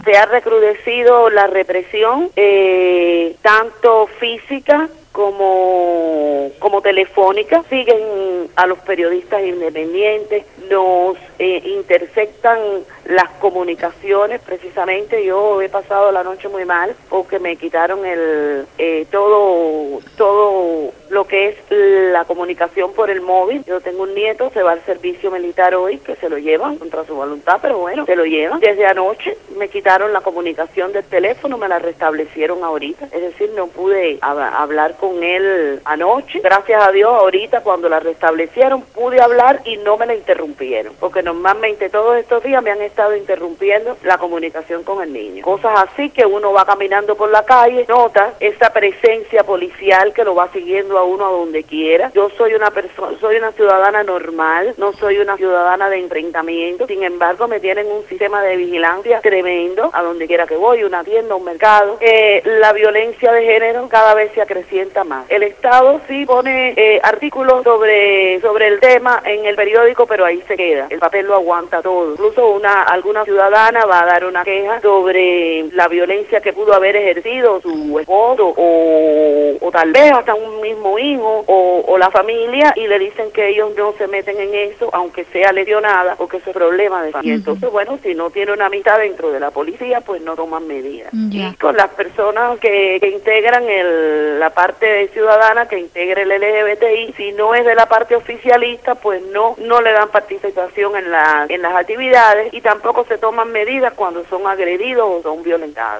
Testimonio ofrecido por la periodista independiente